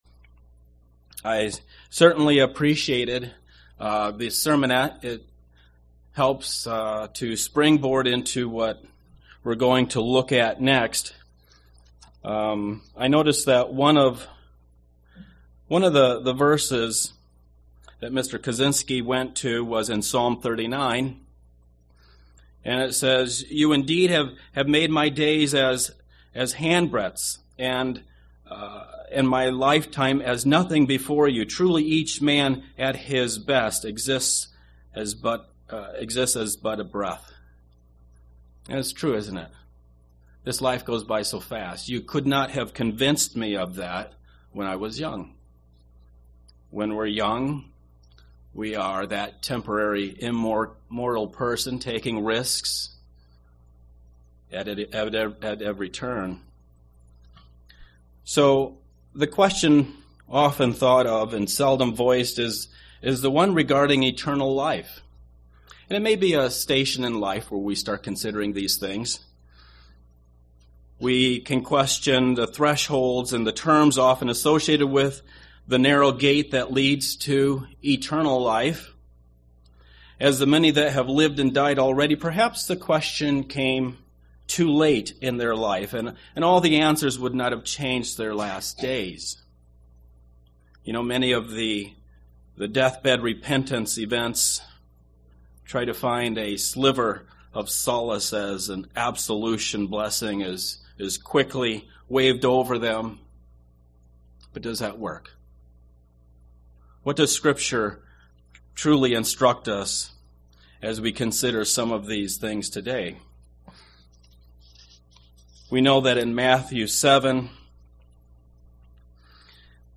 Sermon
Given in Milwaukee, WI